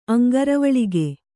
♪ aŋgaravaḷige